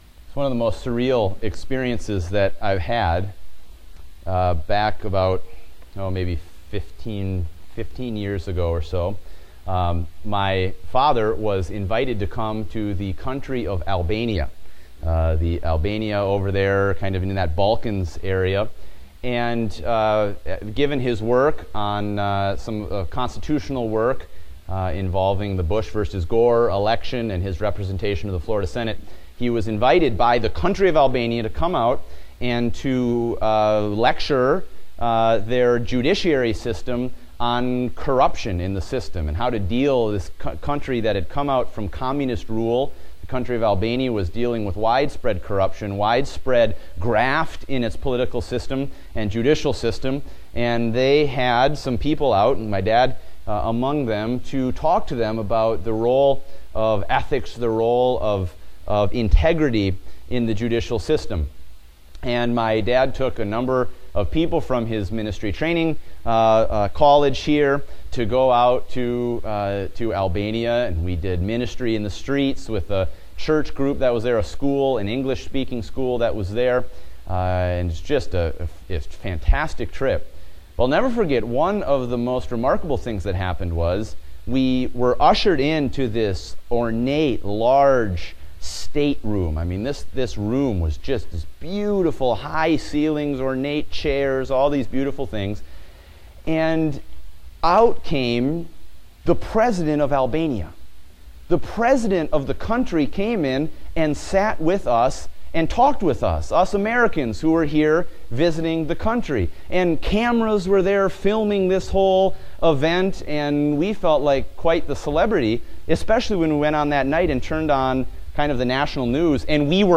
The Son of God AM Service